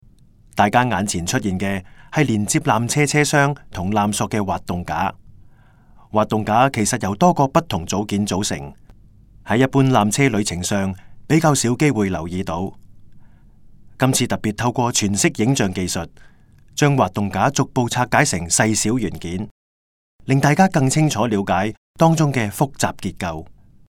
纜車探知館語音導賞 (廣東話)